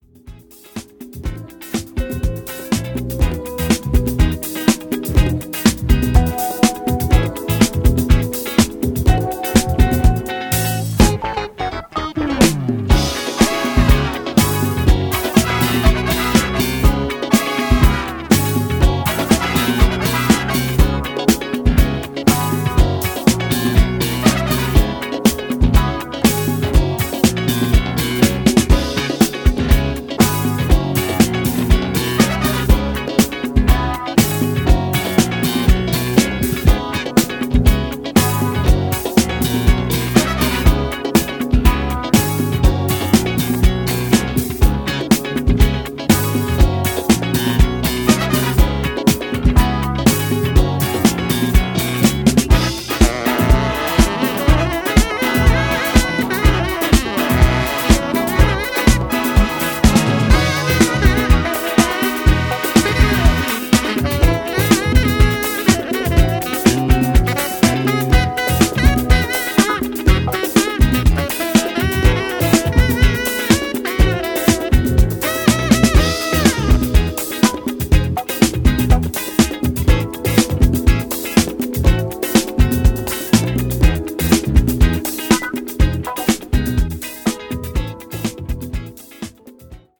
New Release Disco Classics Soul / Funk